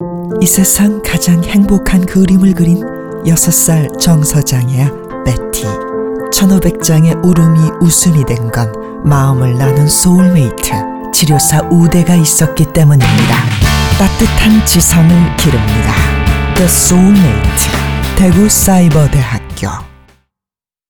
2008학년도 입시모집 라디오 듣기 다운로드
RadioCM_2008_입시모집.wav